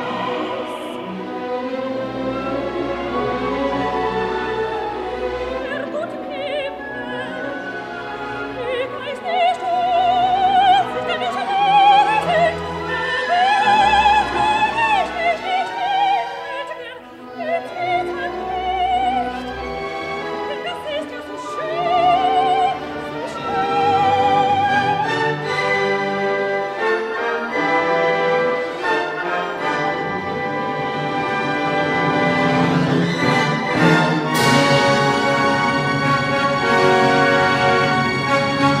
Half note = 92